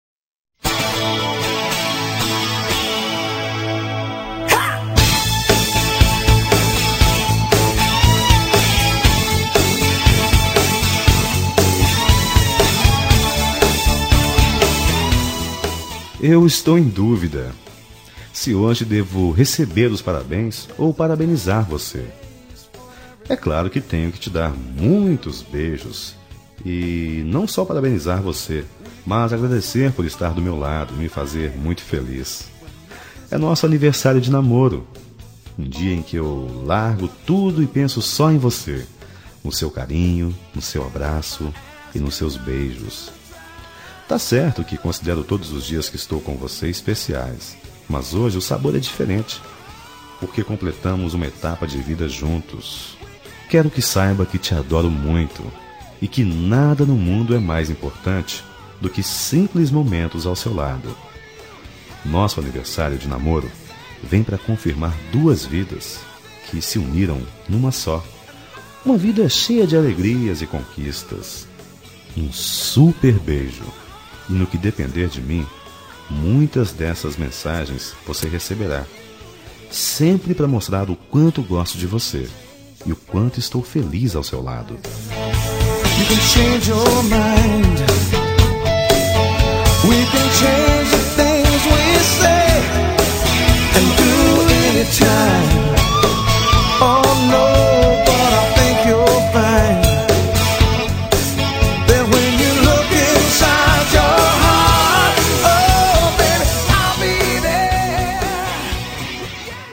ANIVERS�RIO NAMORO ROCK - M - 22 - CD142